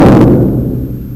Here is the same recording at a faster speed with no tone.